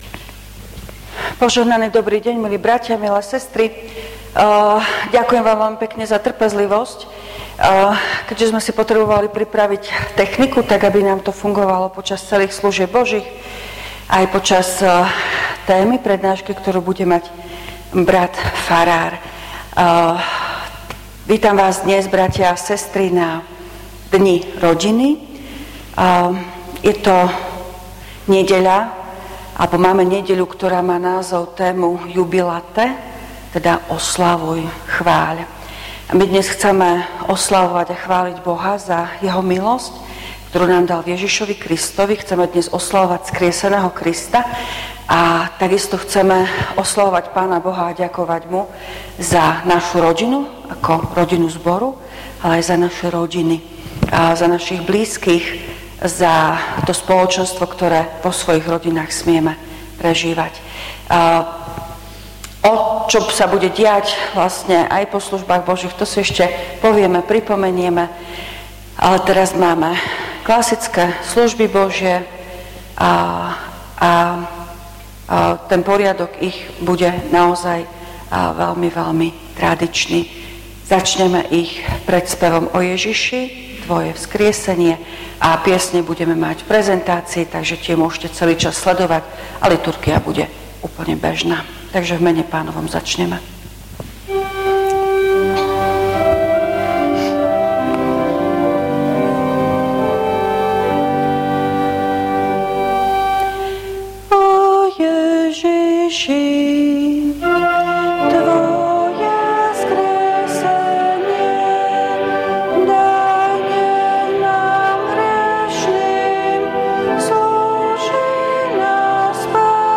V nasledovnom článku si môžete vypočuť zvukový záznam zo služieb Božích – 3. nedeľa po Veľkej noci – Deň rodiny.